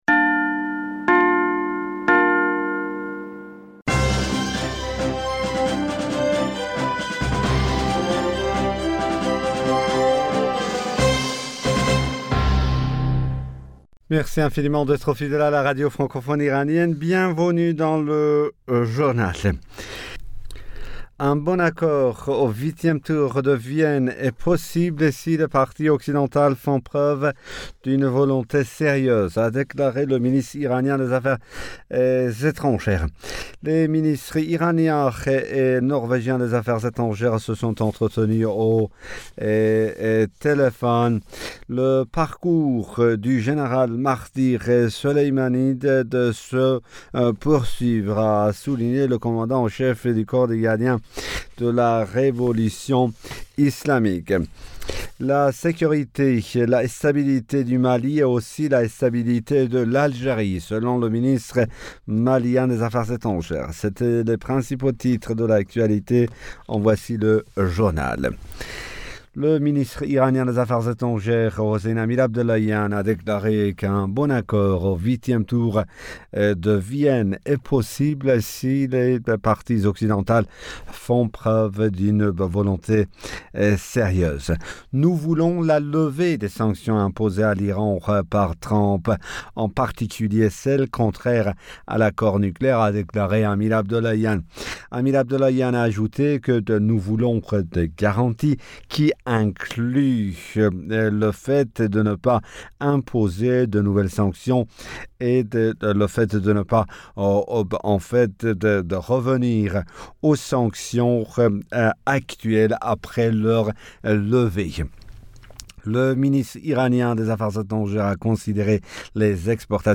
Bulletin d'information Du 07 Janvier 2022